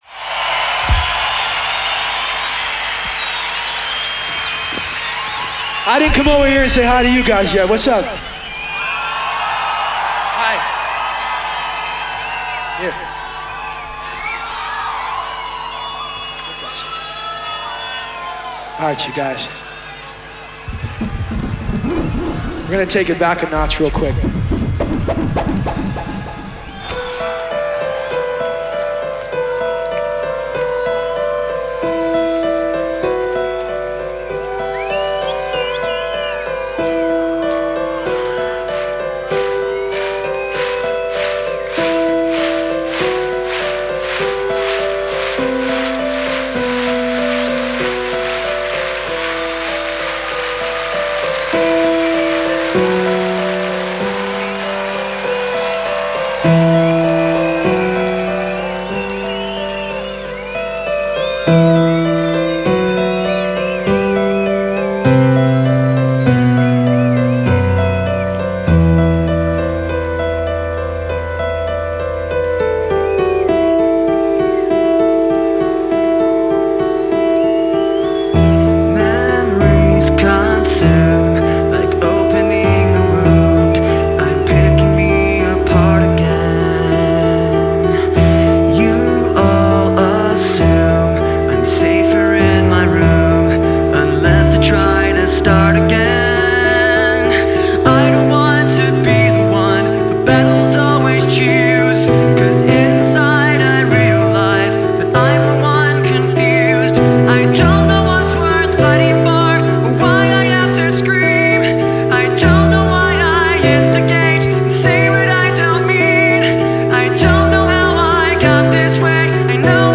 Live Rock Am Ring 2004